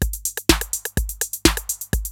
TR-808 LOOP2 4.wav